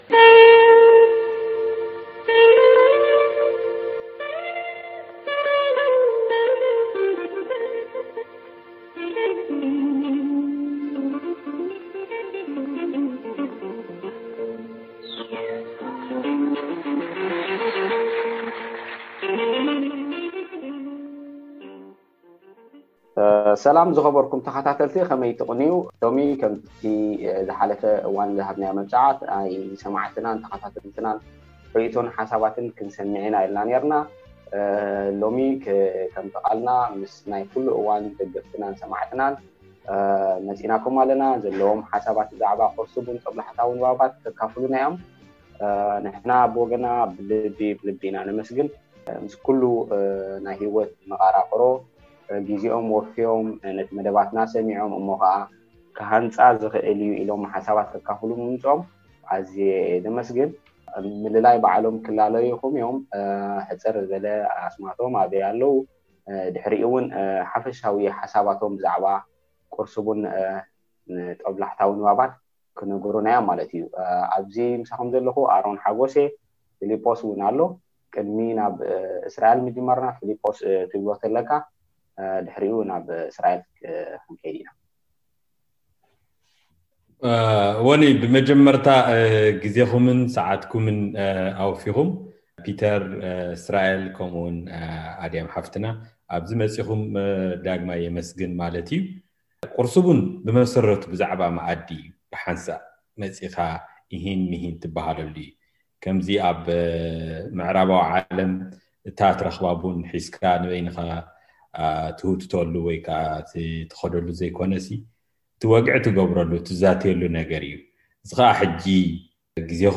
ዕላል ምስ ሰማዕትና